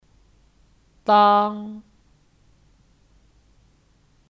当 (Dāng 当)